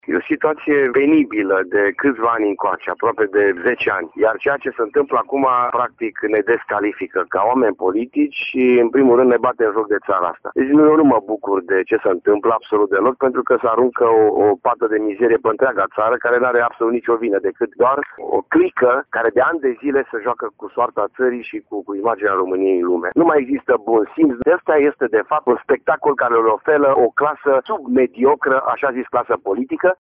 Această situație penibilă descalifică clasa politică din România și aruncă o pată de mizerie pe întreaga țară, spune vicepreședintele PNL Mureș, Dorin Florea: